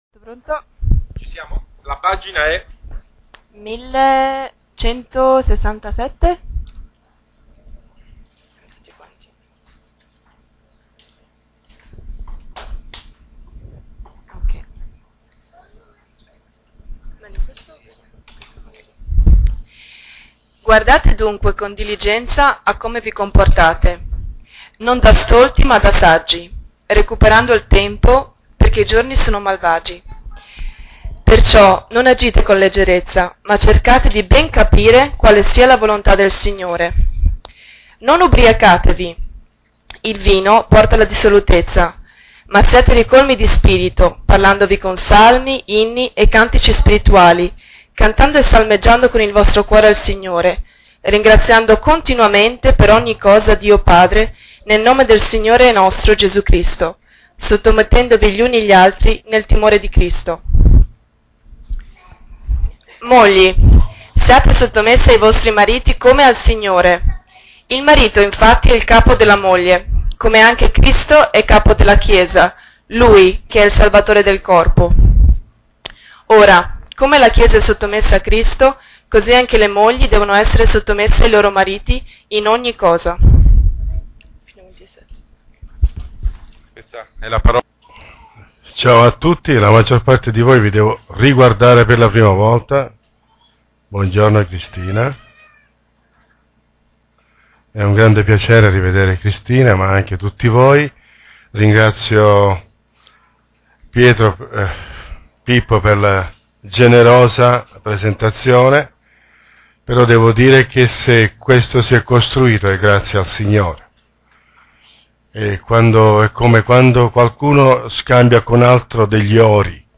Predicazioni